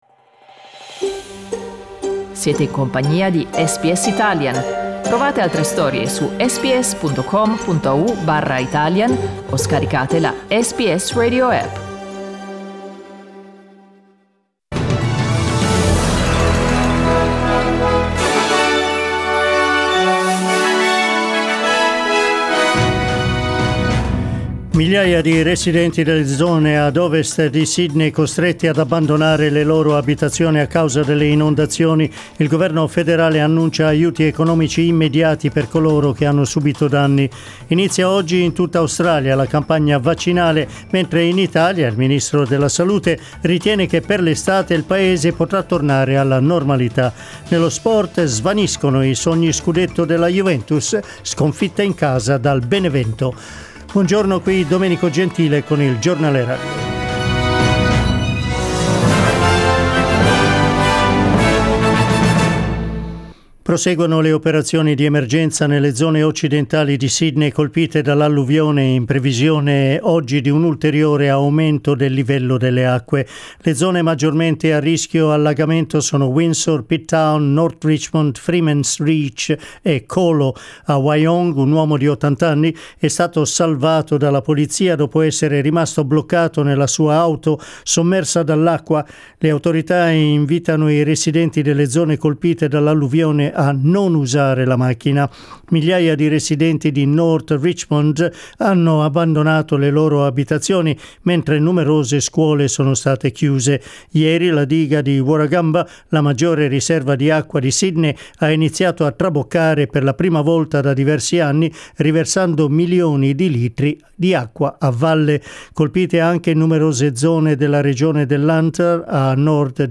Giornale radio lunedì 22 marzo 2021
Il notiziario di SBS in italiano.